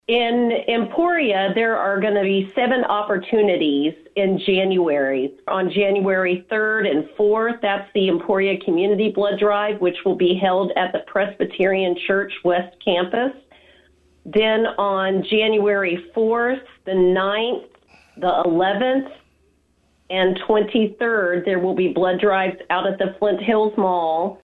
During an interview on KVOE’s Talk of Emporia Wednesday